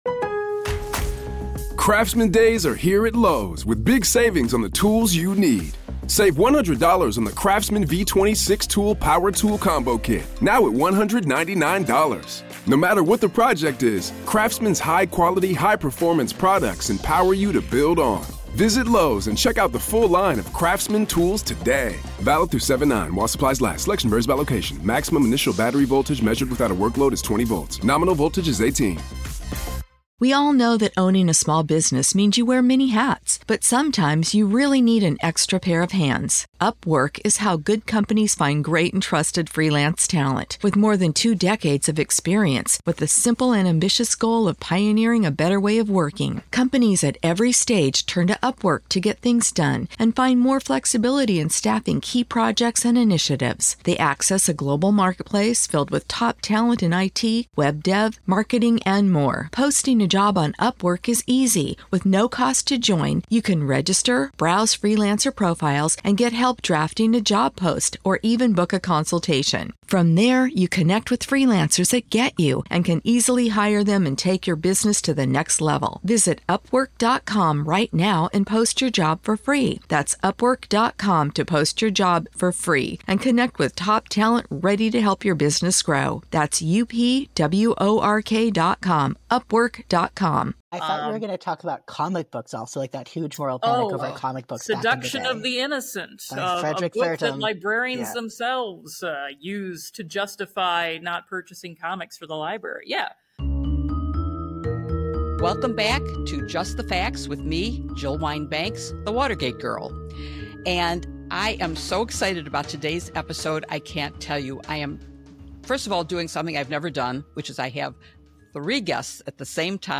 Jill Wine-Banks hosts a panel